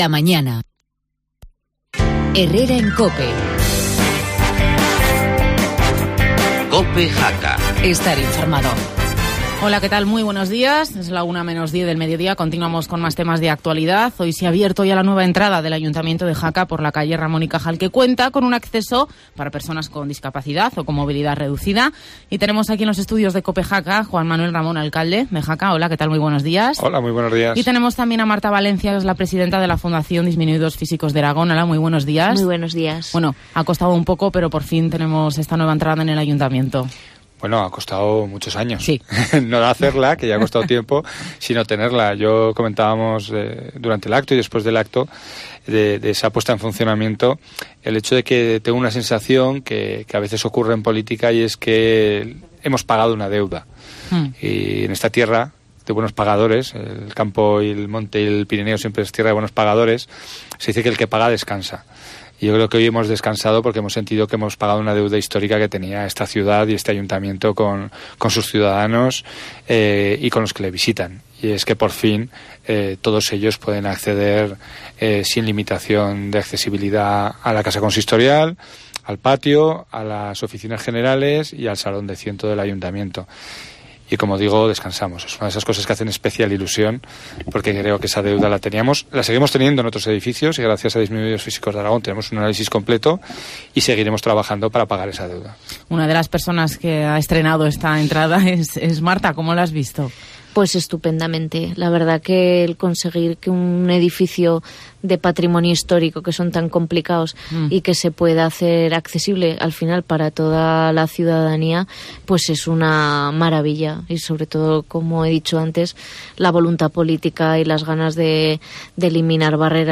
Entrevista en Cope al alcalde de Jaca Juan Manuel Ramón